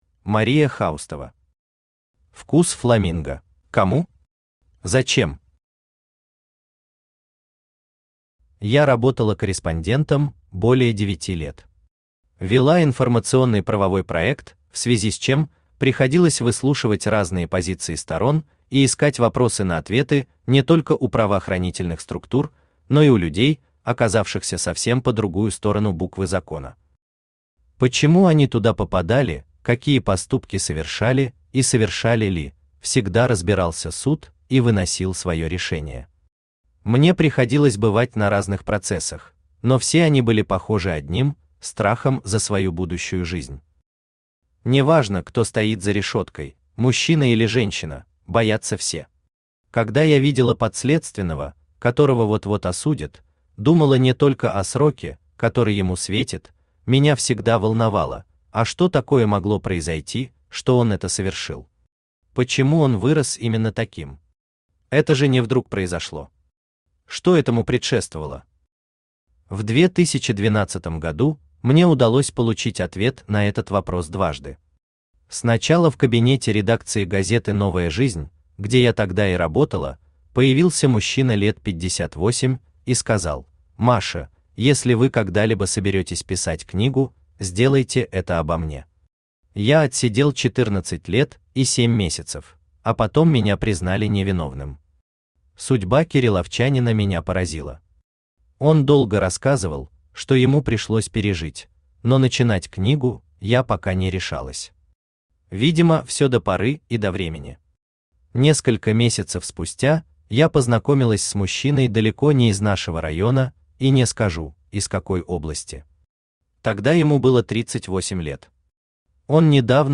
Аудиокнига Вкус фламинго | Библиотека аудиокниг